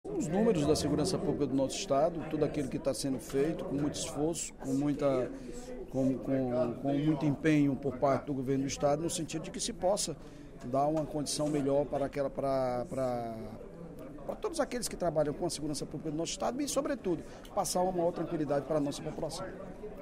O líder do Governo na Casa, deputado Evandro Leitão (PDT), destacou o empenho do governador Camilo Santana no combate à violência no estado do Ceará. Em pronunciamento durante o primeiro expediente da sessão plenária desta quinta-feira (23/03), o líder fez um balanço das ações realizadas na área.